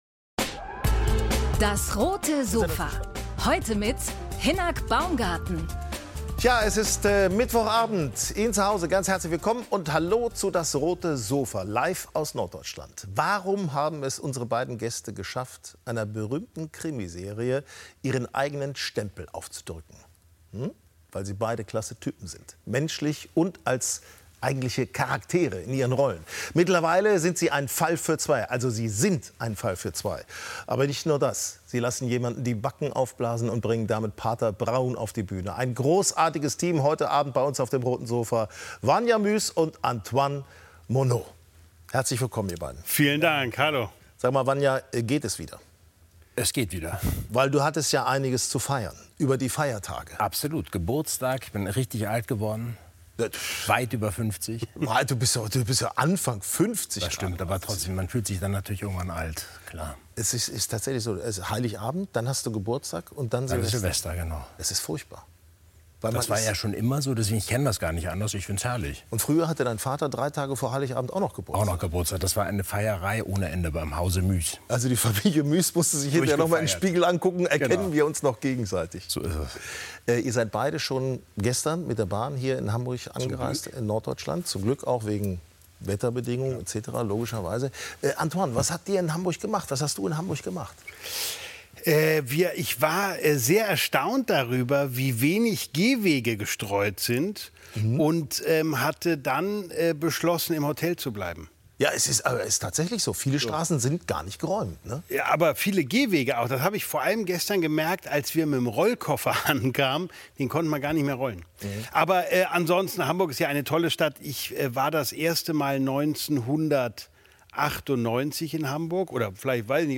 Jetzt gehen sie mit dem Hörspiel „Pater Brown" auf Tour – untermalt von Live-Beatbox. Bei DAS! sprechen die beiden Schauspieler über ihre Bühnentour, ihre langjährige Zusammenarbeit und persönliche Geschichten...